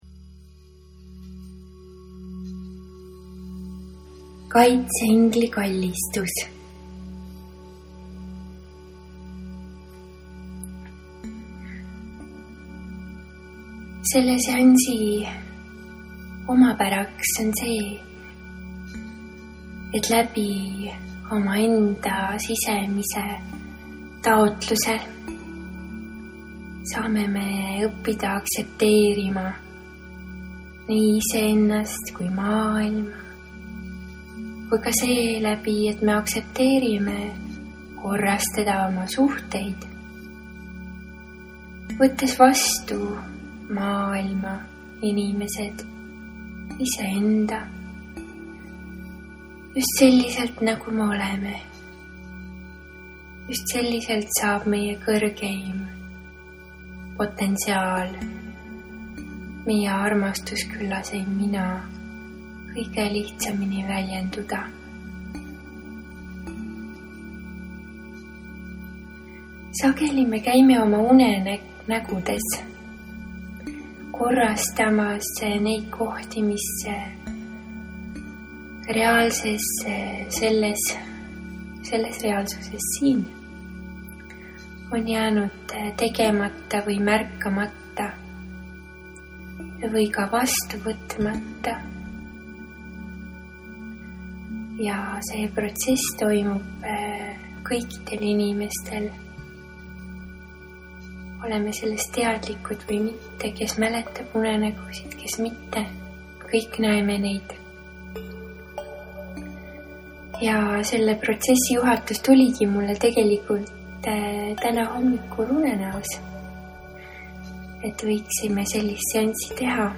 Meditatsioon Kaitseingli kallistus